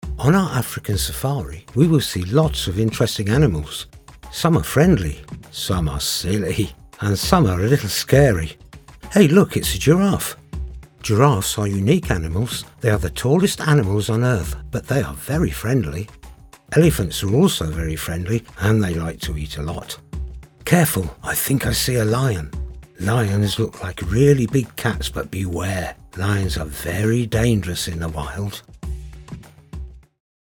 My natural voice is neutral British English, clear, friendly and easy to listen to with the ability to vary the dynamics to suit the project.
Documentary
Words that describe my voice are Neutral British, Clear, Expressive.